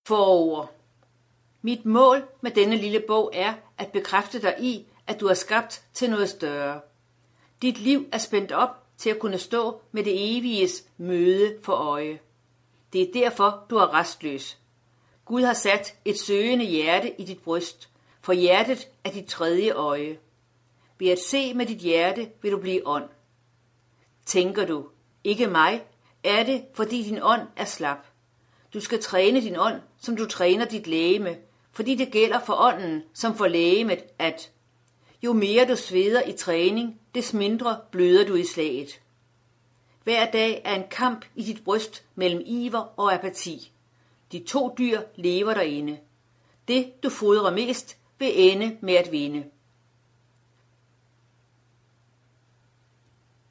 Hør et uddrag af Længsel
Lydbog